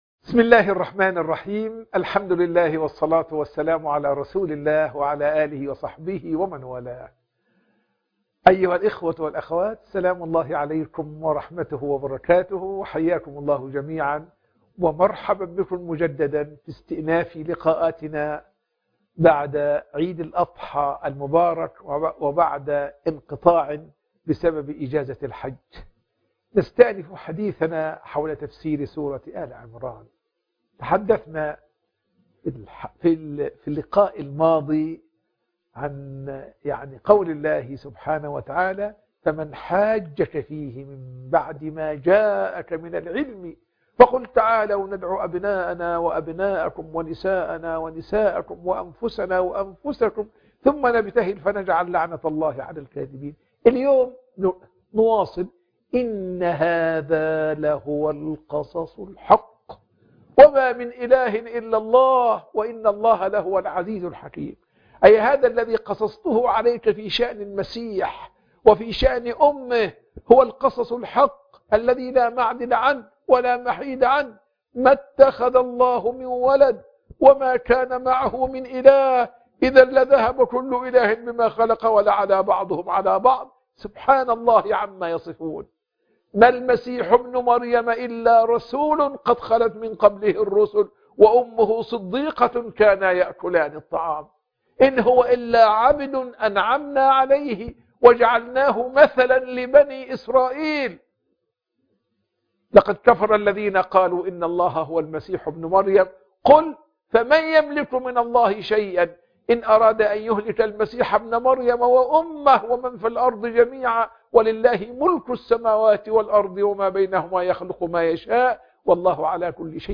تفسير سورة آل عمران 62 (محاضرات التفسير)